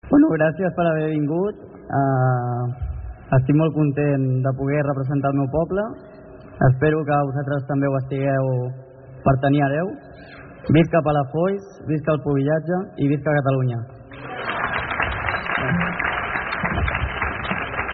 A la tarda es va seguir amb una cercavila, on hi participaven un centenar d’hereus i pubilles d’arreu de Catalunya, i que va acabar a la plaça de Poppi, on es va fer la proclamació dels nous hereu i pubilla.